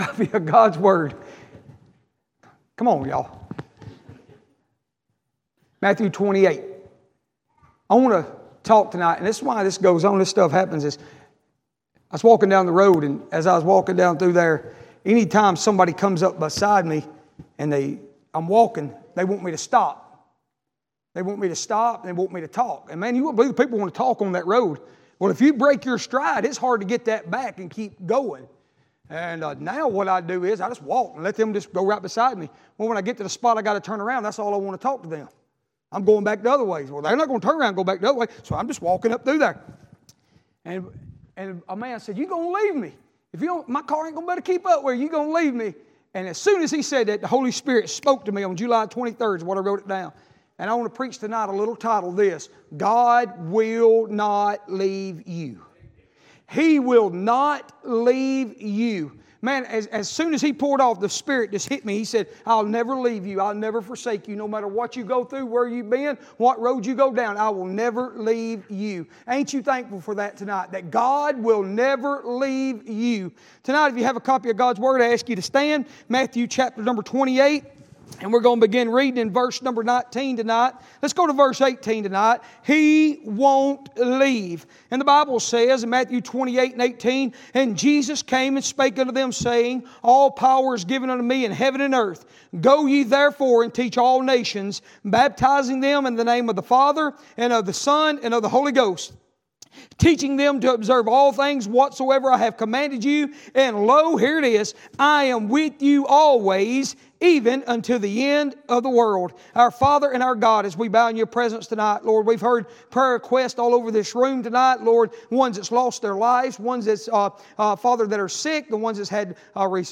SERMONS - Union Chapel Baptist